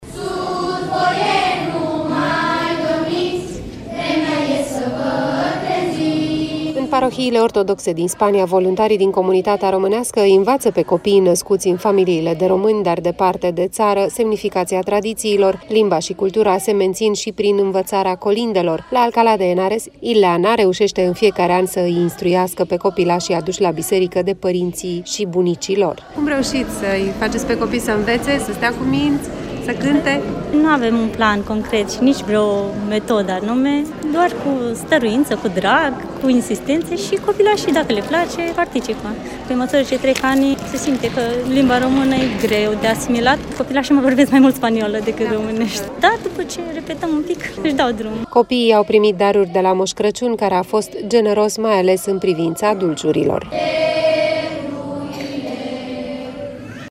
Colindele româneşti au răsunat ieri în mai multe biserici din Spania, unde sute de români au sărbătorit Crăciunul în mod tradiţional, ca acasă. O corespondenţă